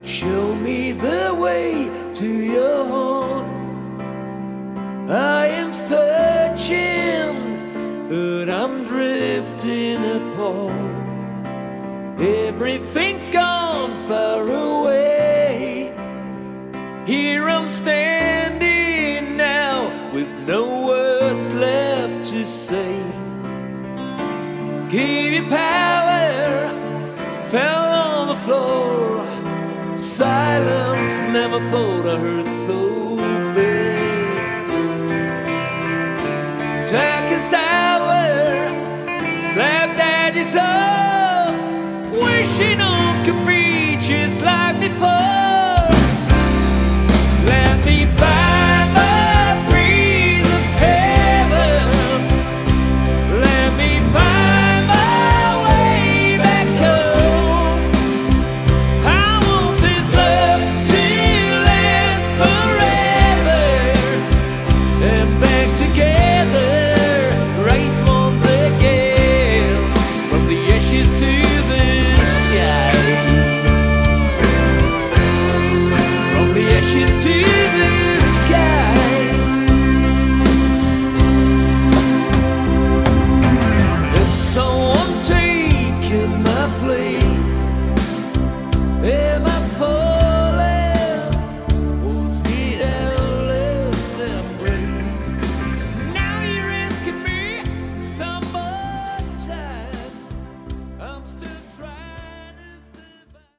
• Rockband
• Coverband